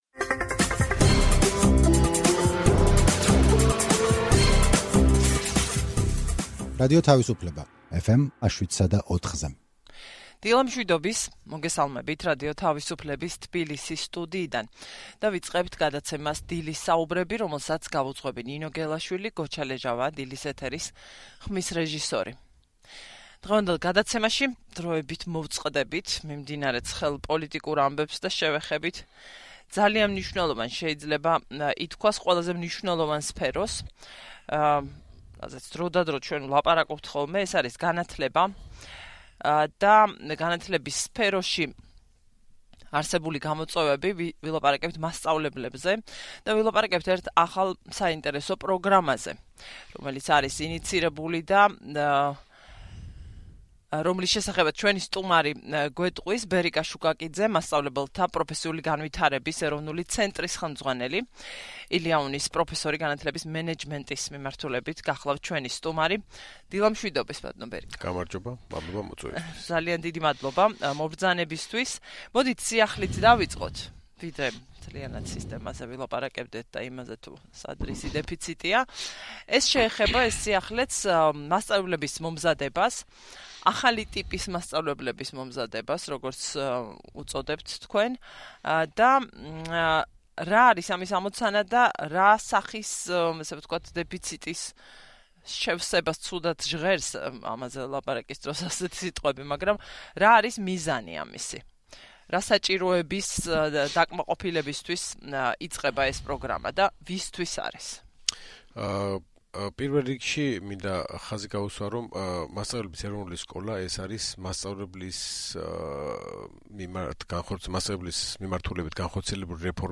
რადიო თავისუფლების ეთერში ლაპარაკობს გზებზე, რომლებმაც ბავშვებისთვის საინტერესო უნდა გახადოს სკოლა.